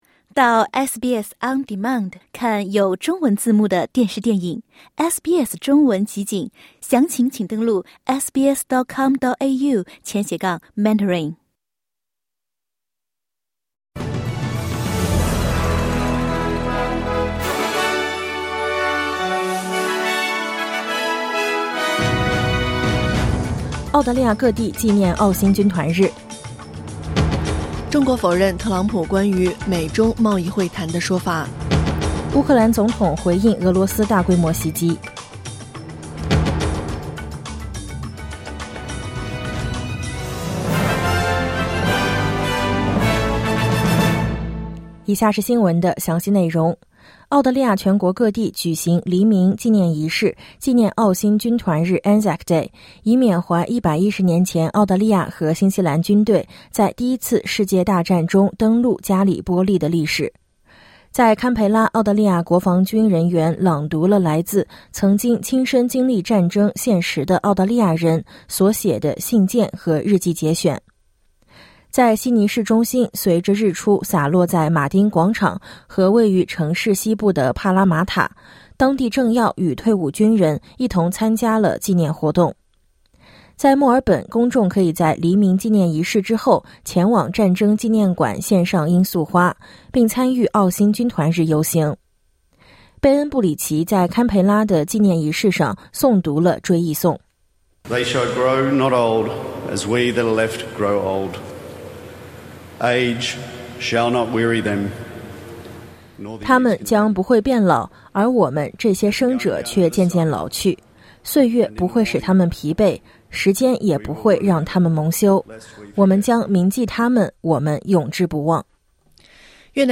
SBS早新闻（2025年4月25日）